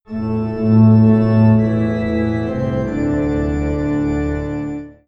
organ plays in the background.